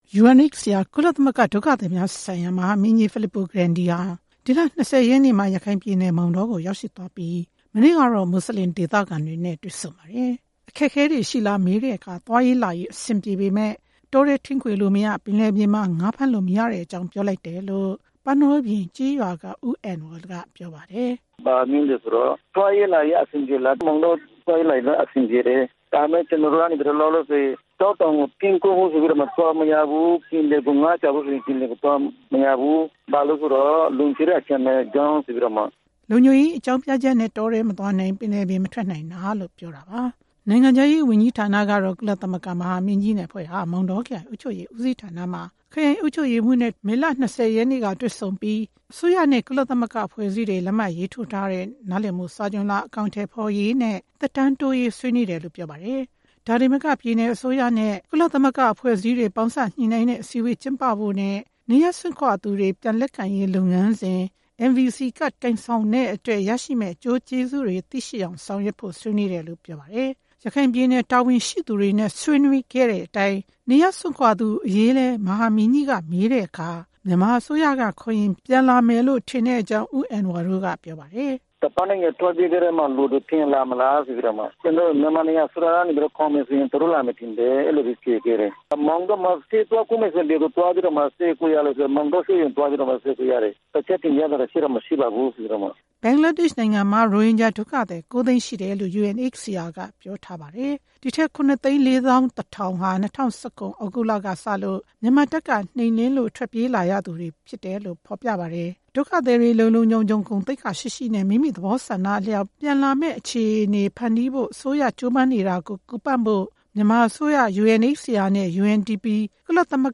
UNHCR ကုလသမဂ္ဂ ဒုက္ခသည်များဆိုင်ရာ မဟာမင်းကြီး Fillippo Grandi နဲ့ တွေ့ဆုံချိန်မှာ သွားရေးလာရေး အဆင်ပြေပေမဲ့ လုပ်ကိုင်စားသောက်ဖို့ မလွယ်ကူတဲ့အကြောင်း တင်ပြတယ်လို့ ရခိုင်ပြည်နယ် မောင်တောမြို့နယ်က မွတ်ဆလင် ဒေသခံတယောက်က ပြောပါတယ်။